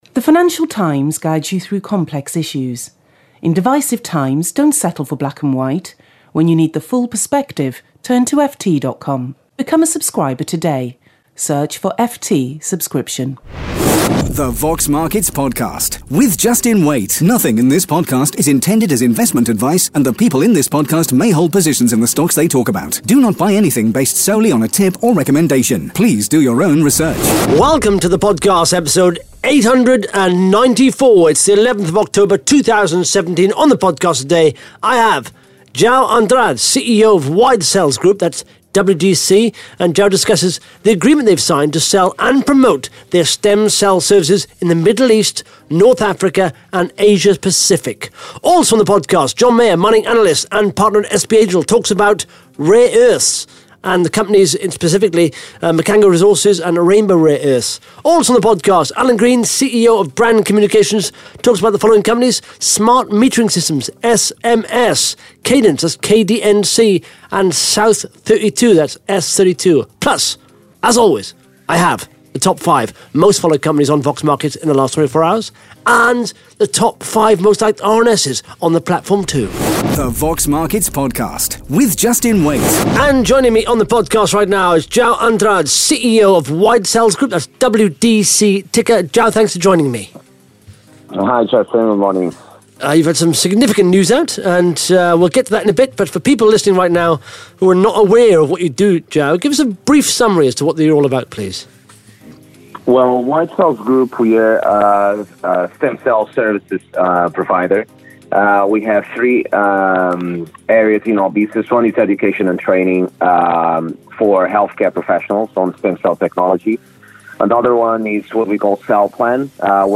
(Interview starts at 1 minute 19 seconds)